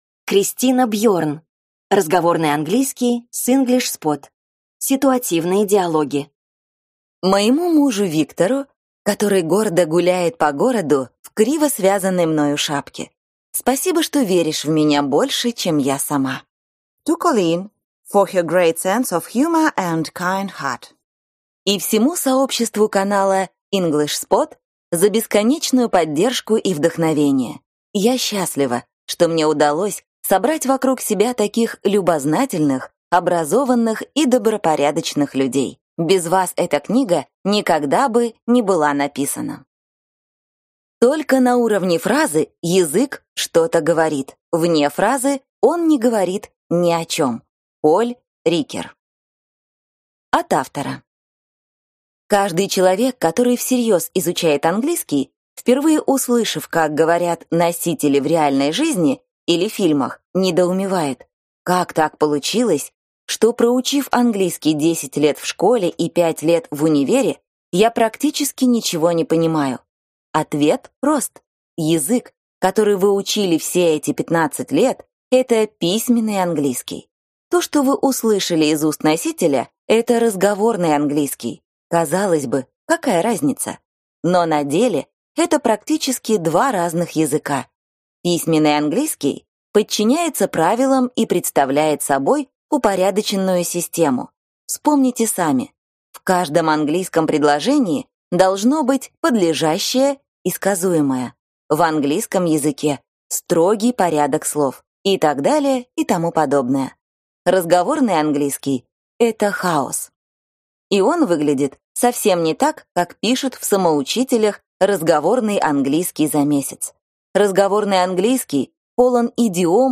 Аудиокнига Разговорный английский с English Spot. Ситуативные диалоги | Библиотека аудиокниг